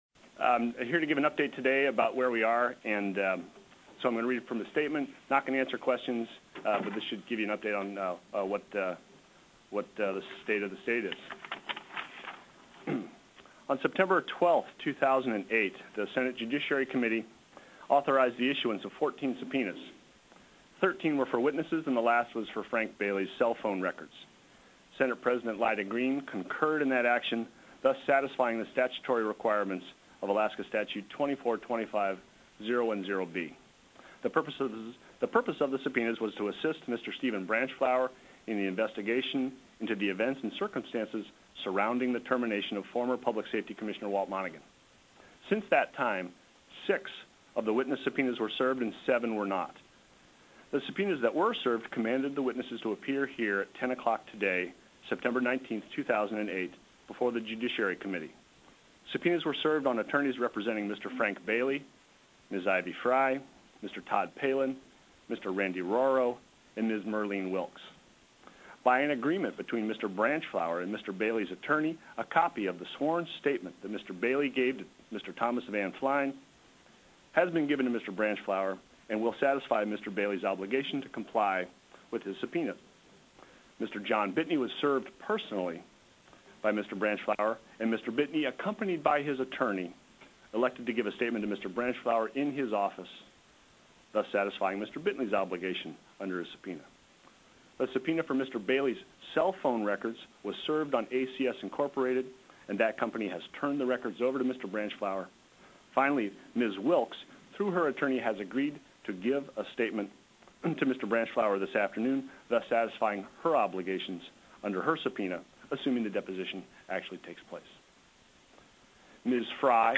Legislature(2007 - 2008)Anch LIO Conf Rm
09/19/2008 10:00 AM Senate JUDICIARY
+ teleconferenced
Senator Hollis French read the following statement: